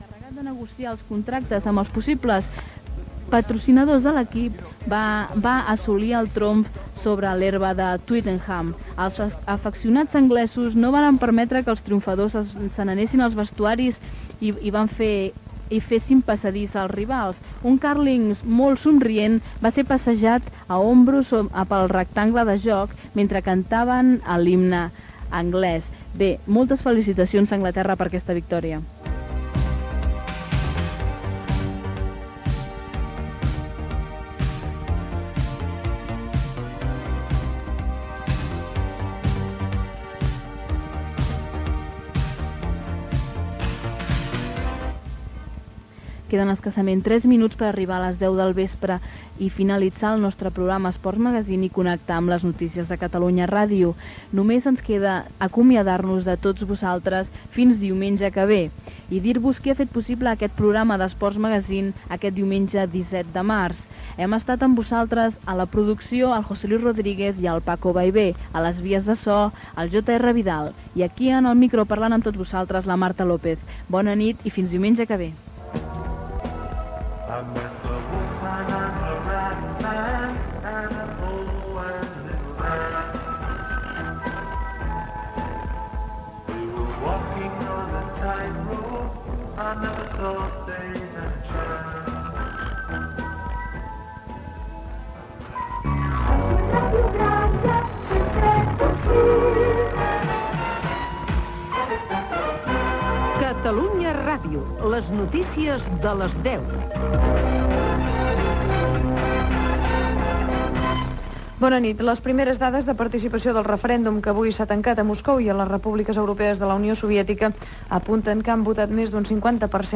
Final del programa, noms de l'equip, indicatiu de l'emissora i connexió amb l'informatiu de Catalunya Ràdio: referèndum a la Unió Soviètica.
Esportiu
FM